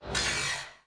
Block Sawblade Object Sound Effect
block-sawblade-object.mp3